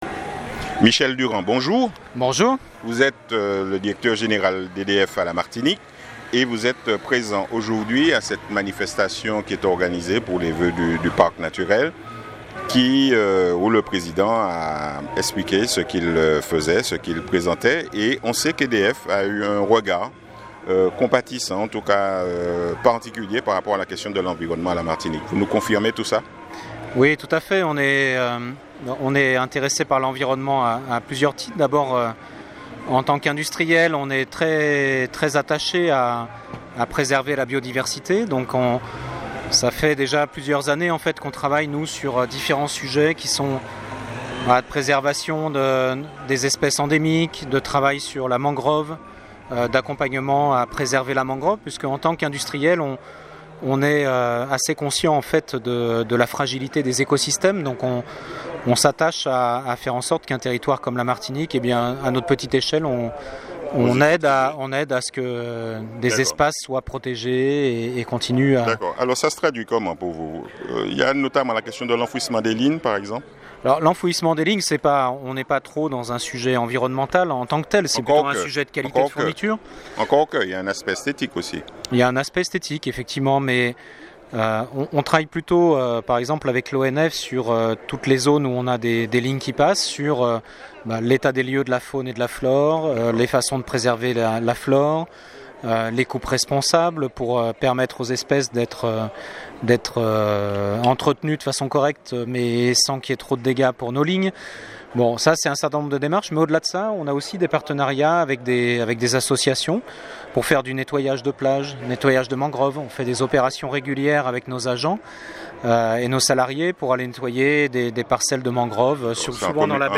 Beaucoup d'informations à cette occasion dont une qui nous a particulièrement interpellé. 40 000 foyers martiniquais, ont du mal à payer leurs factures d'électricité. Pour lancer cette ITW, appuyer sur le bouton de démarrage.